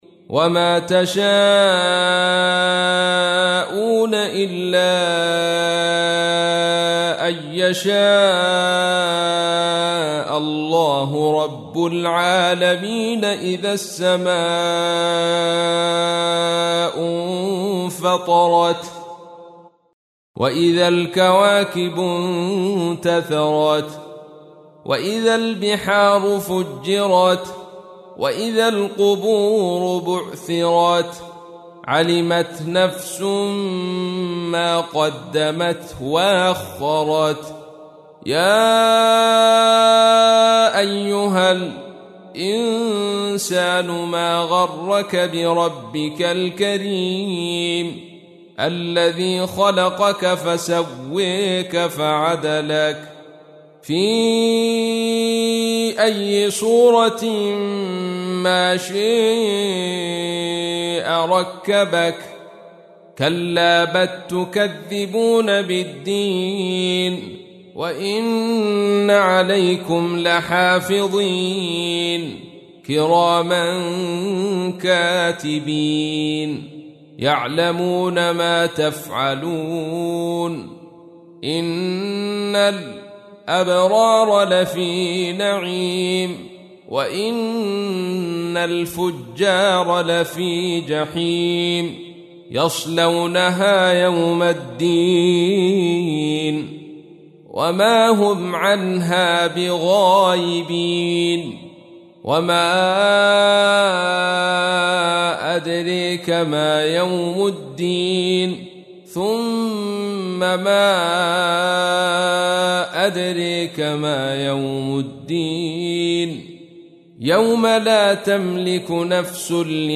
تحميل : 82. سورة الانفطار / القارئ عبد الرشيد صوفي / القرآن الكريم / موقع يا حسين